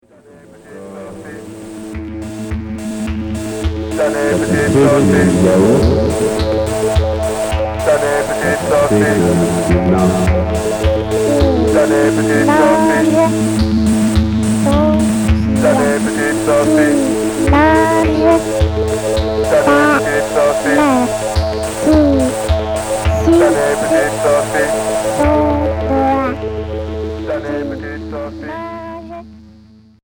Punk industriel Unique 45t retour à l'accueil